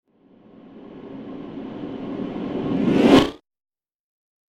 دانلود آهنگ باد 78 از افکت صوتی طبیعت و محیط
دانلود صدای باد 78 از ساعد نیوز با لینک مستقیم و کیفیت بالا
جلوه های صوتی